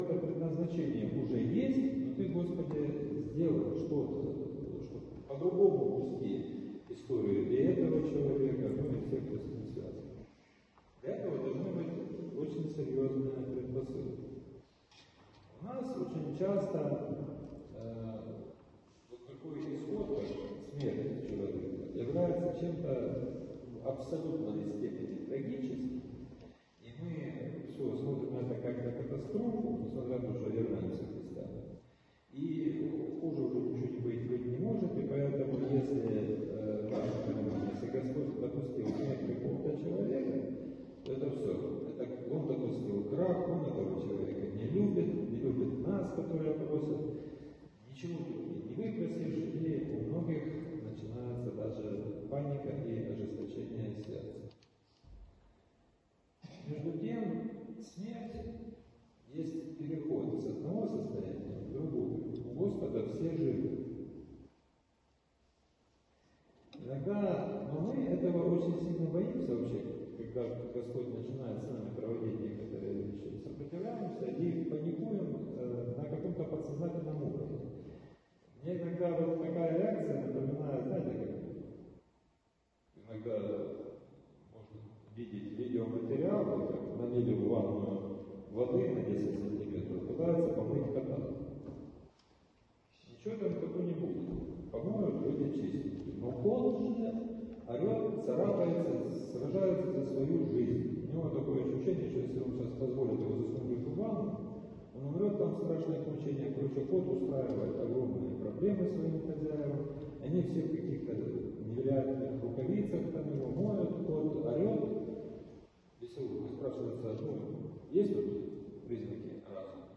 Проповедь в двадцать третью неделю по Пятидесятнице